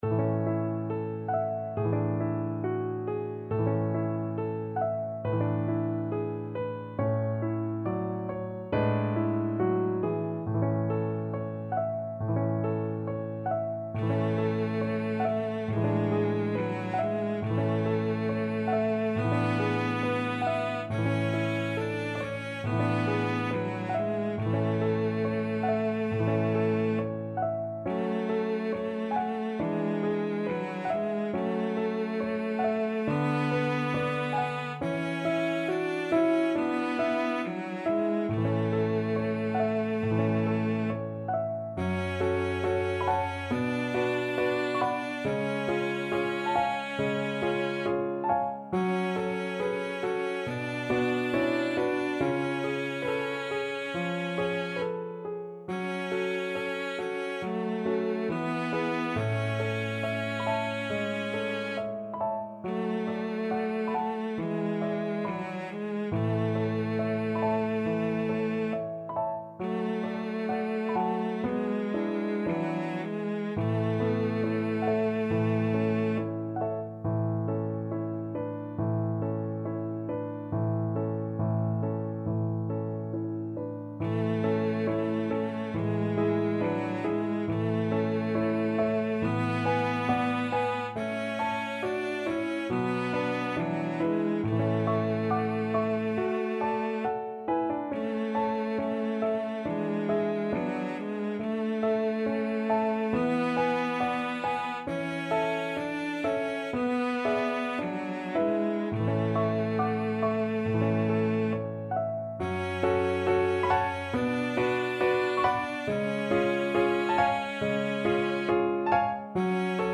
~ = 69 Andante tranquillo
4/4 (View more 4/4 Music)
Classical (View more Classical Cello Music)